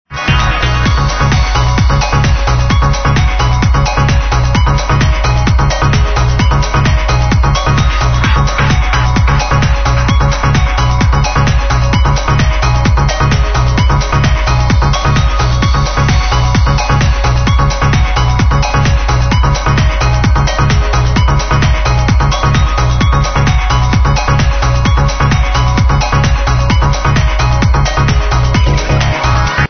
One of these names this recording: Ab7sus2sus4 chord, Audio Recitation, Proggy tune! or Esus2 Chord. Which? Proggy tune!